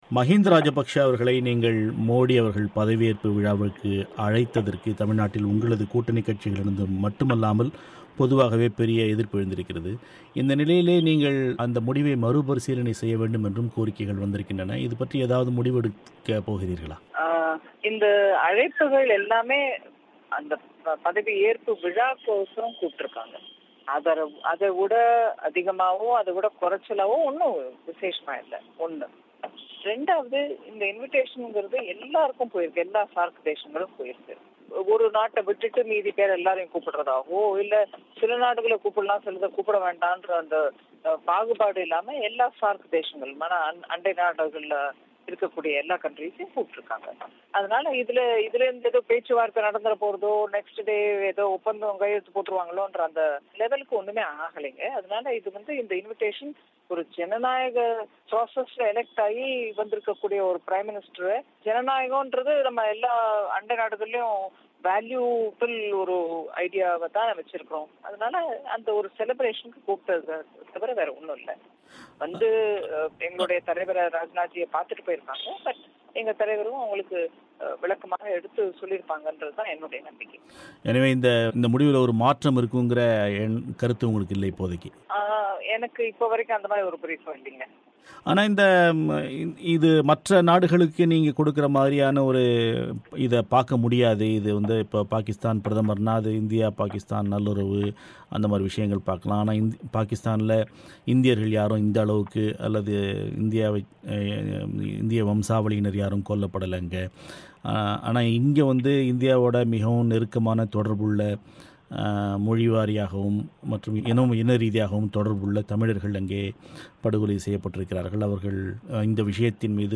பிபிசி தமிழோசைக்கு அளித்த பேட்டியில், இந்த அழைப்பு என்பது மஹிந்த ராஜபக்ஷவுக்கு மட்டும் தனியாக அனுப்பப்பட்ட ஒன்றில்லை.